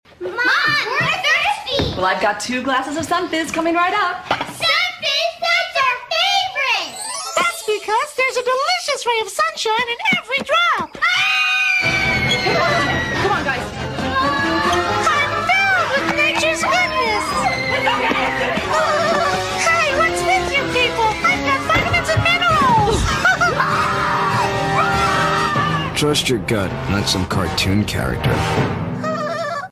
Forgotten tv ad from the 90’s